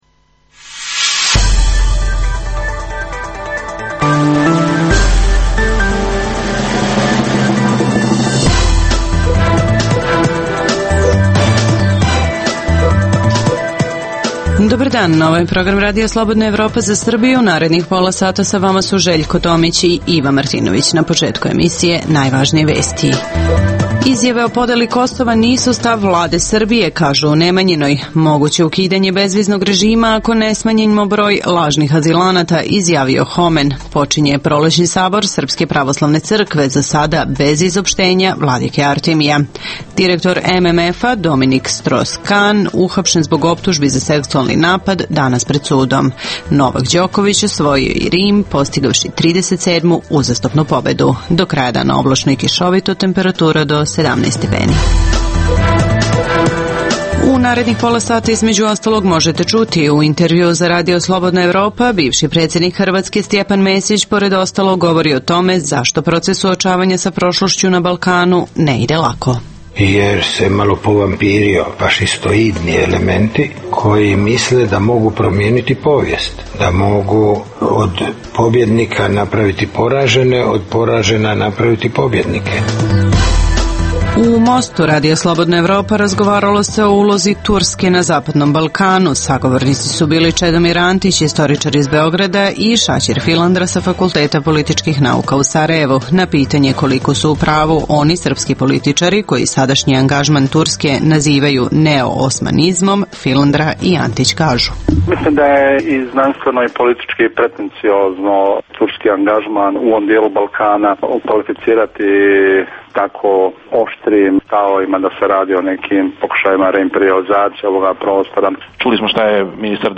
U ovoj emisiji možete čuti: - U intervjuu za RSE, bivši predsednik Hrvatske Stjepan Mesić, pored ostalog govori, o tome zašto proces suočavanja sa prošlošću na Balkanu ne ide lako. - U Mostu RSE razgovaralo se o ulozi Turske na Balkanu.